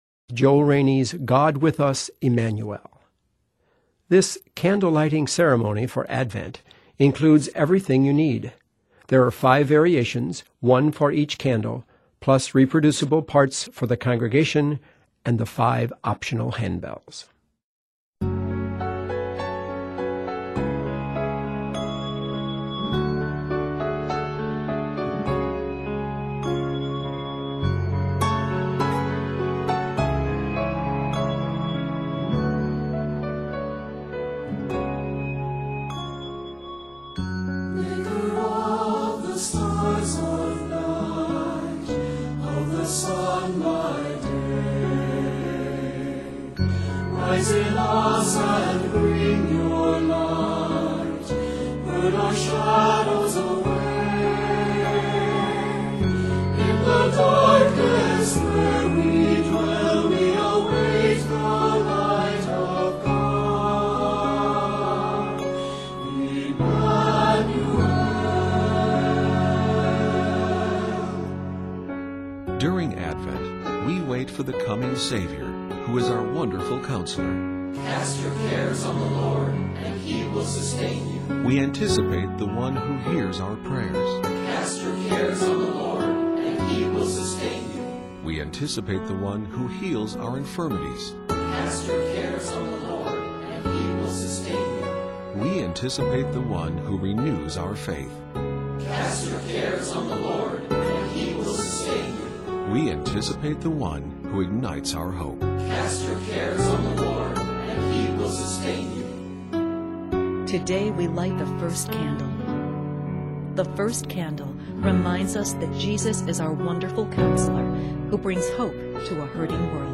Set mostly in f# minor, this anthem is 67 measures.